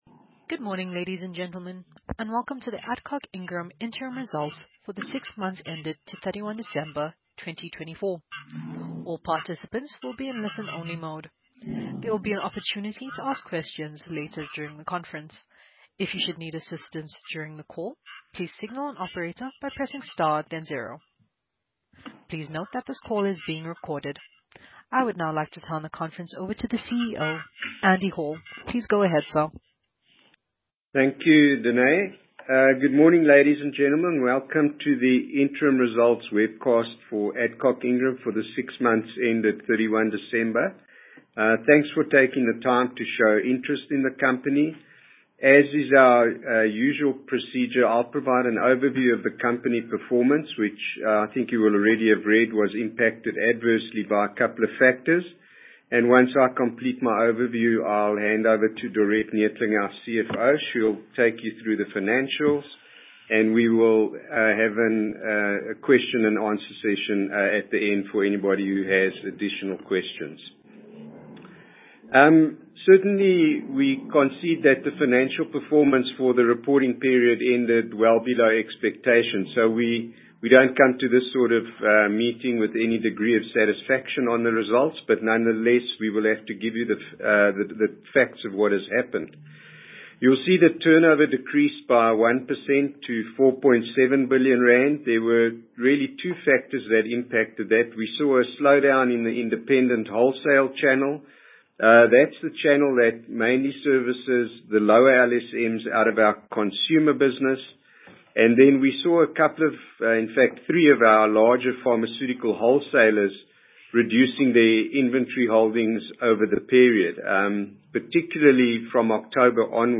Post Results Conference Call Audio File